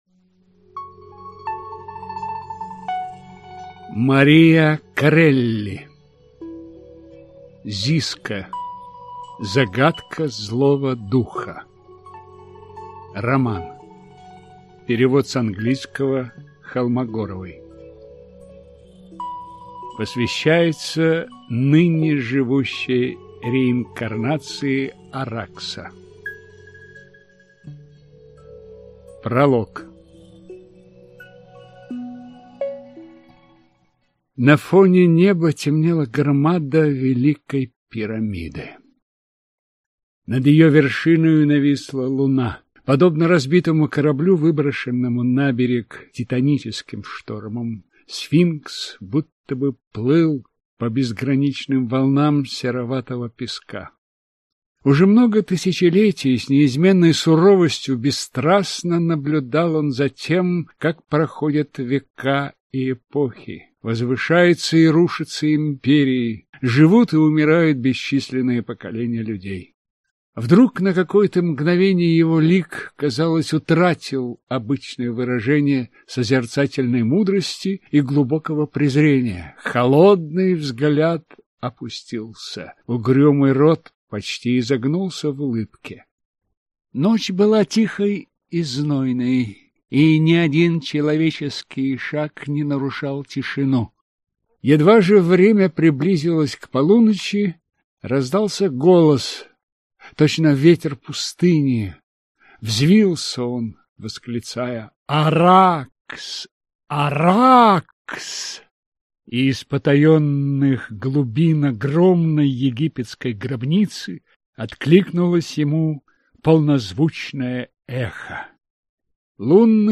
Аудиокнига Зиска. Загадка злого духа | Библиотека аудиокниг